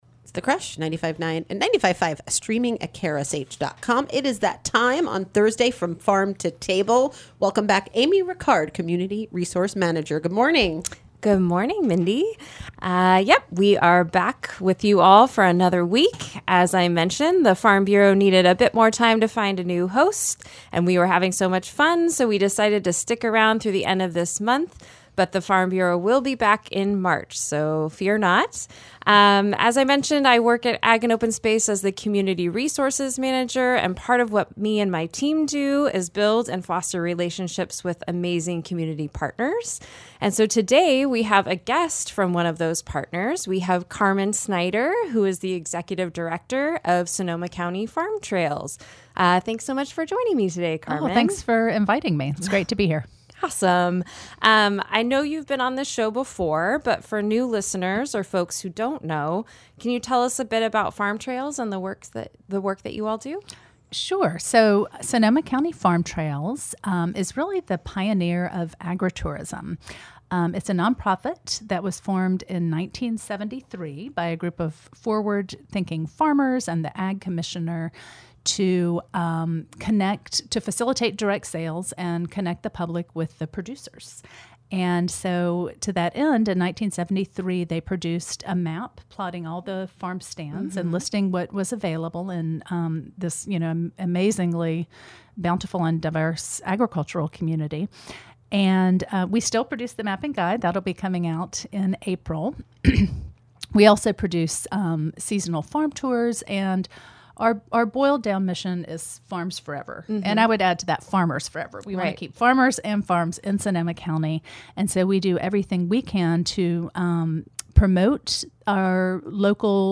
Catch recordings of the From Farm to Table radio show for conversations with Ag + Open Space about land stewardship and conservation!
Interview